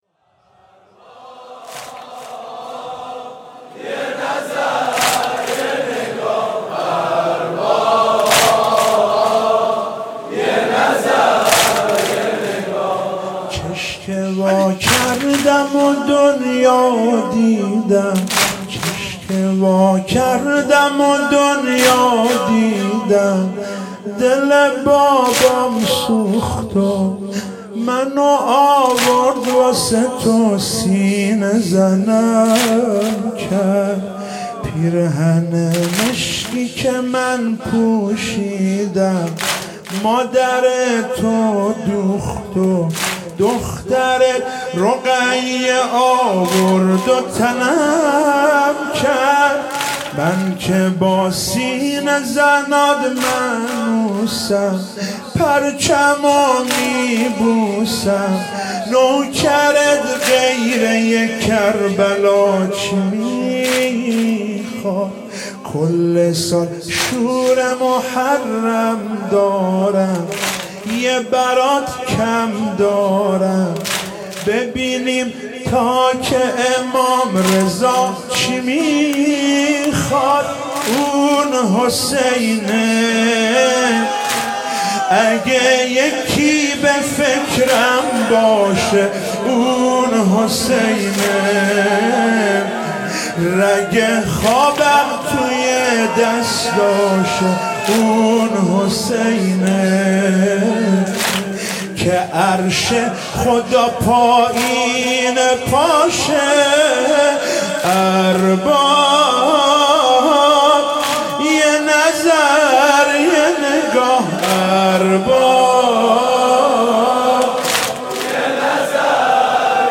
شهادت حضرت زینب سلام الله علیها
واحد مداحی